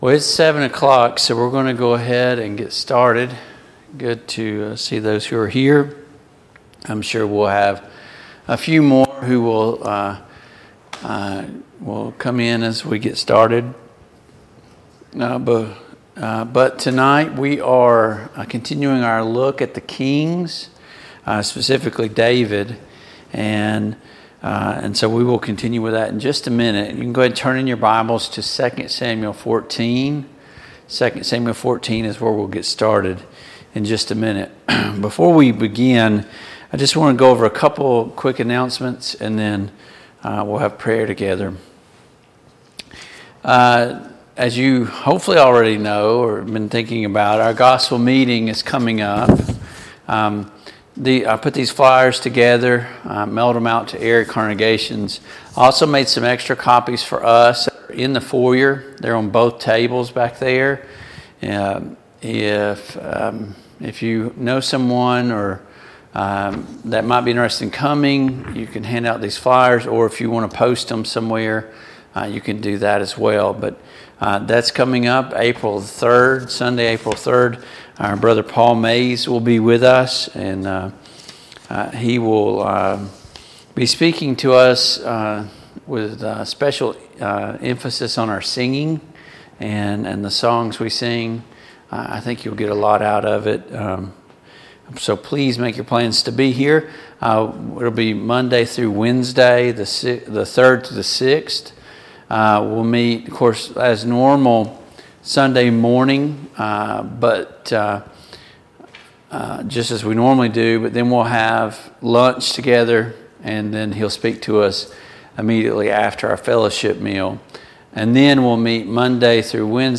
The Kings of Israel Passage: II Samuel 14, II Samuel 15 Service Type: Mid-Week Bible Study Download Files Notes « 2.